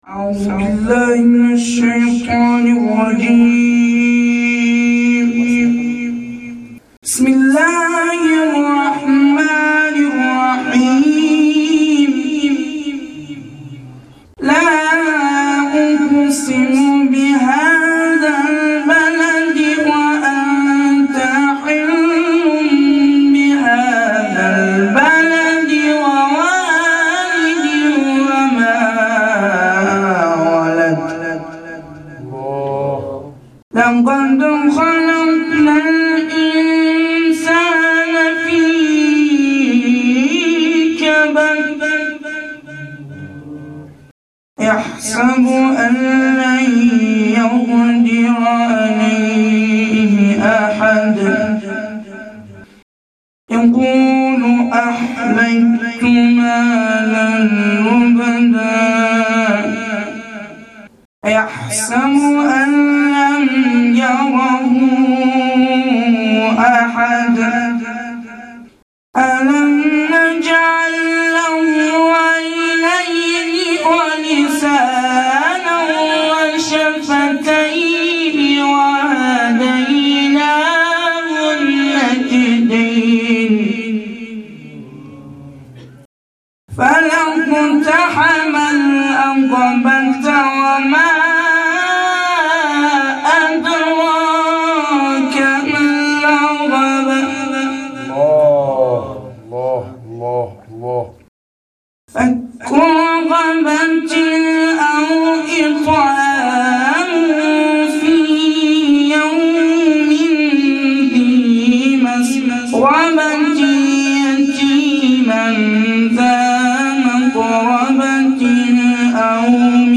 قرائت شب پنجم فاطمیه 1393
هیئت رایت الهدی کمالشهر
مداحی فاطمیه